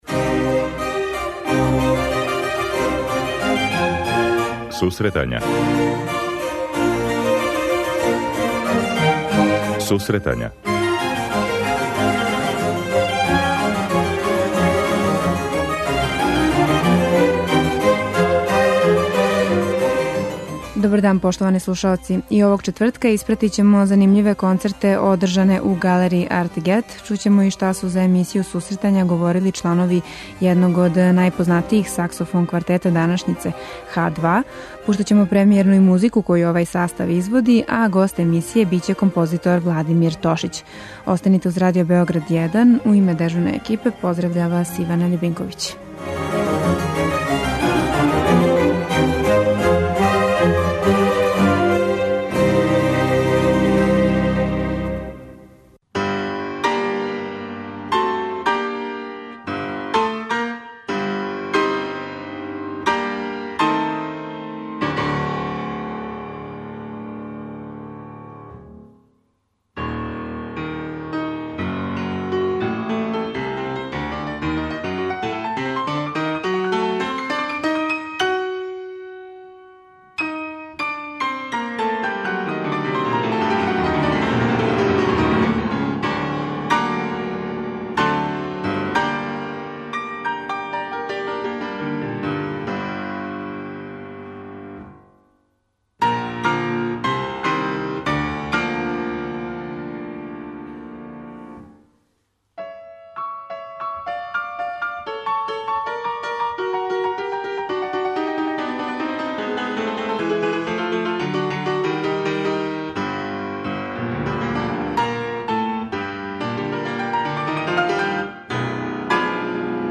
У данашњој емисији премијерно ћемо слушати музику саксофон квартета „H2“ као и разговар са члановима тог састава.